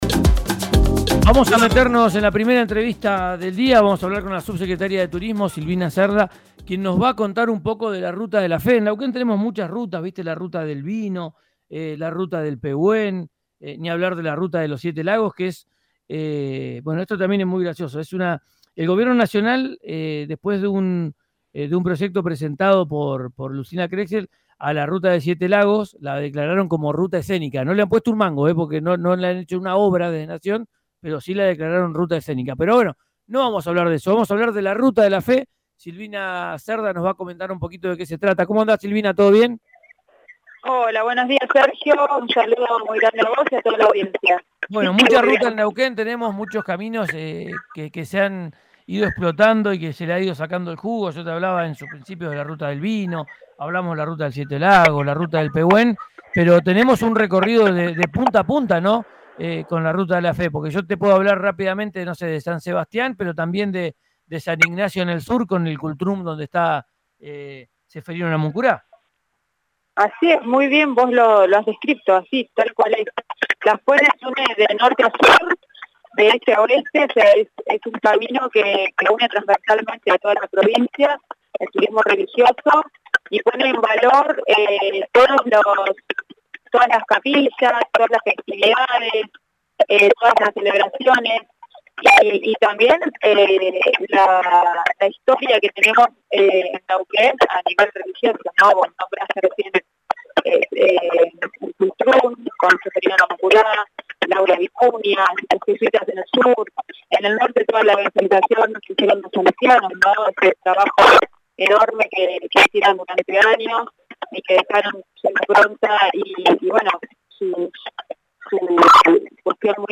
Escuchá a Silvina Cerda en “Ya es tiempo de vacaciones”, por RÍO NEGRO RADIO
En diálogo con RÍO NEGRO RADIO, Silvina Cerda, subsecretaria de Turismo de la provincia es «un camino que pone en valor las festividades celebraciones y la historia que tenemos en Neuquén a nivel religioso».